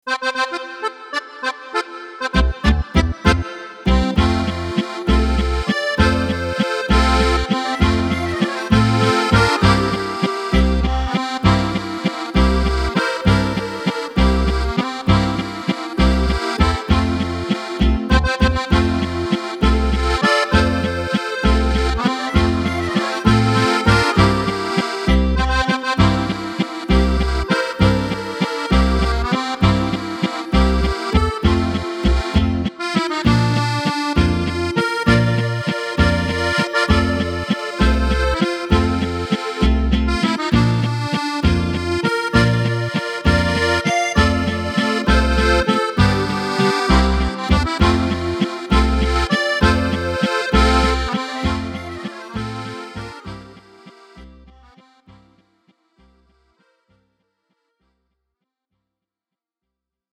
Tempo: 198 / Tonart: F – Dur